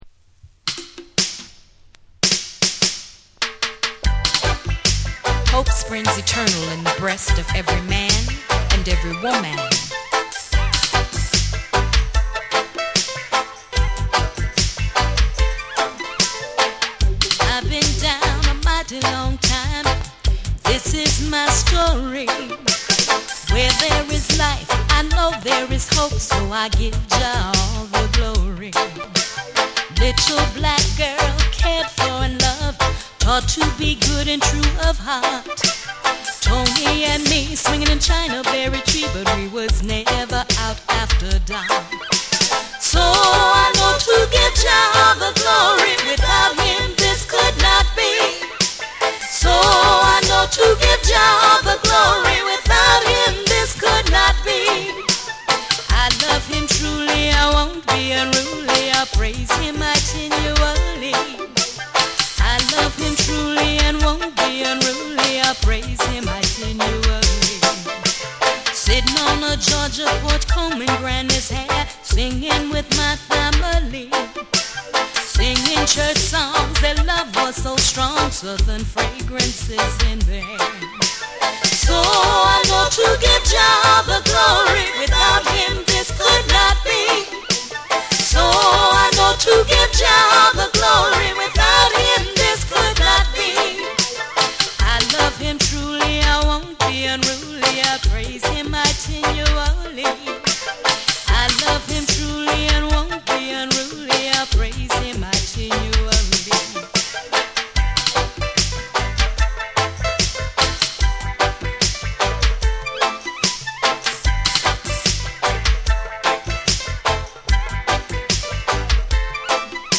温かく力強いヴォーカルと深いグルーヴが融合し、心を揺さぶるレゲエを収録。
REGGAE & DUB